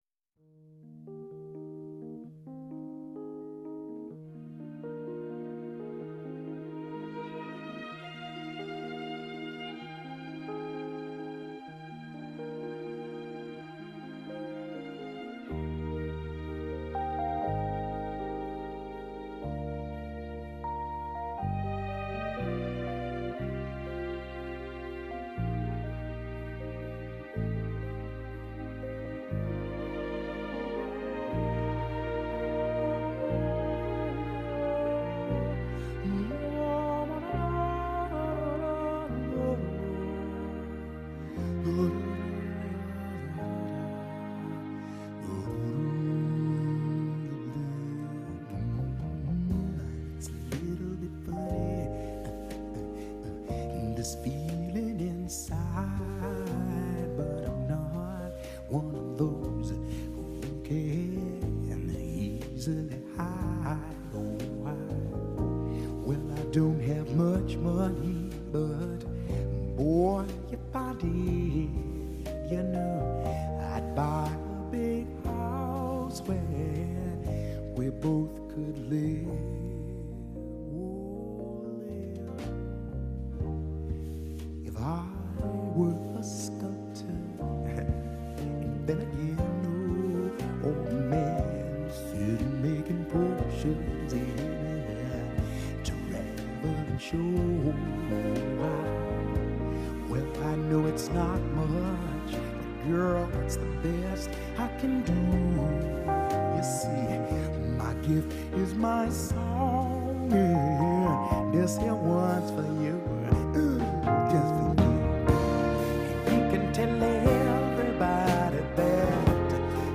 Ο 9,58fm στο 64ο Διεθνές Φεστιβάλ Κινηματογράφου Θεσσαλονίκης
στο στούντιο του 9,58fm, στην Αποθήκη Γ , στο Λιμάνι της Θεσσαλονίκης